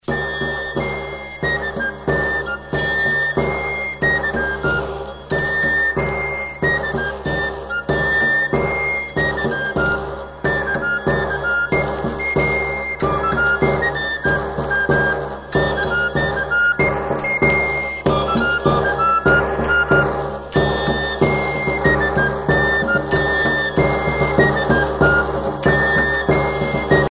danse : matelote
Pièce musicale éditée